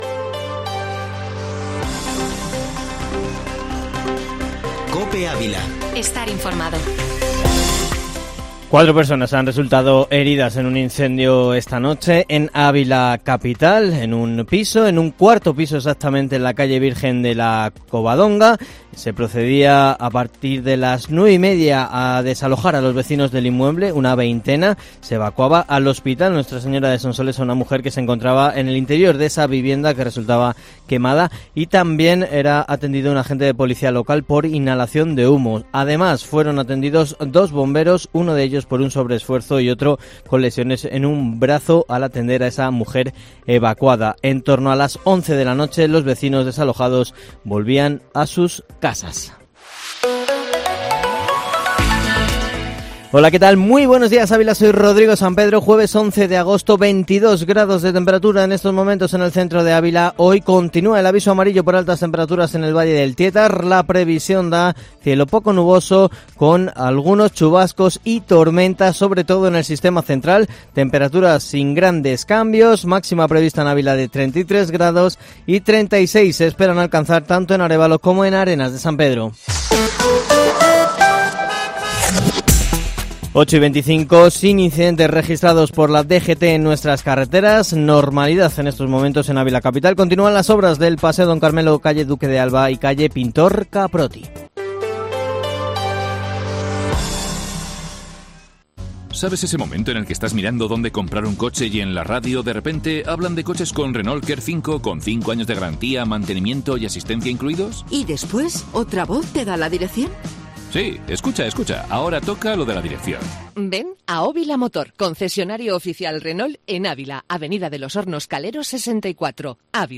Informativo Matinal Herrera en COPE Ávila, información local y provincial